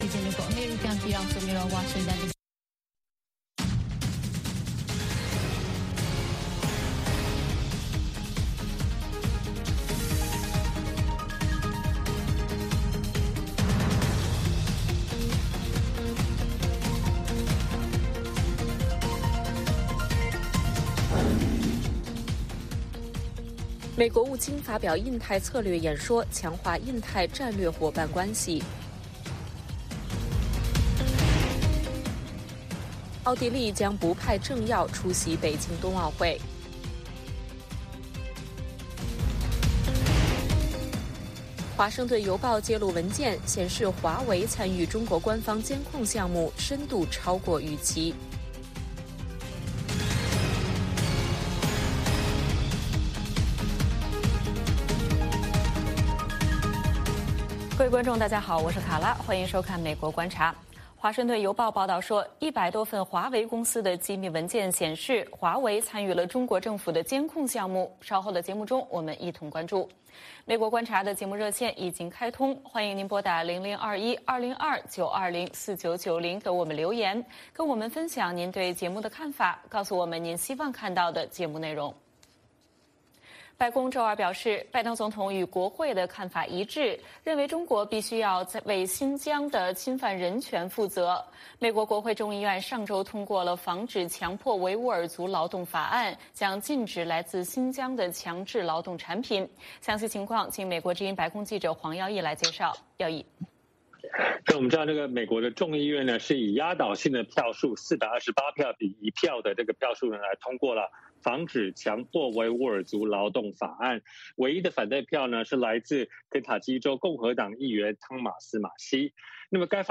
北京时间早上6点广播节目，电视、广播同步播出VOA卫视美国观察。 “VOA卫视 美国观察”掌握美国最重要的消息，深入解读美国选举，政治，经济，外交，人文，美中关系等全方位话题。节目邀请重量级嘉宾参与讨论。